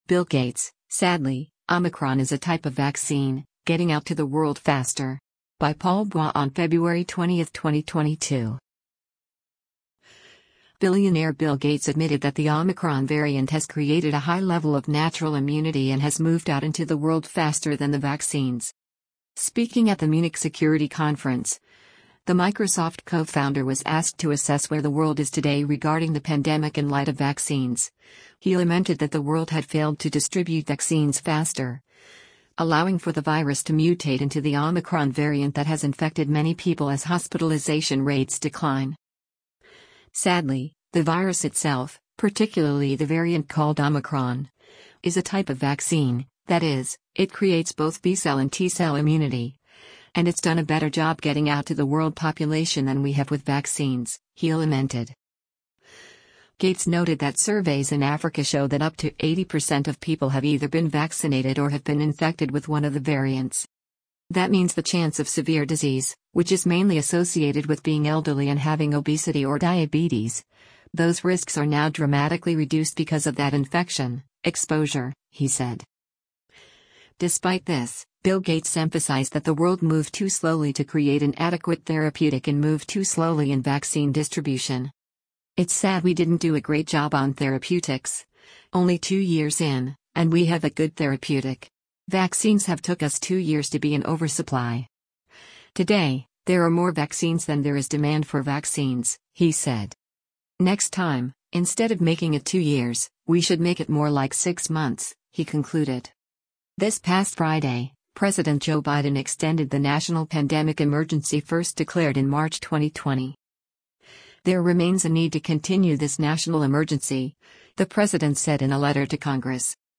Speaking at the Munich Security Conference, the Microsoft co-founder was asked to assess where the world is today regarding the pandemic in light of vaccines; he lamented that the world had failed to distribute vaccines faster, allowing for the virus to mutate into the omicron variant that has infected many people as hospitalization rates decline.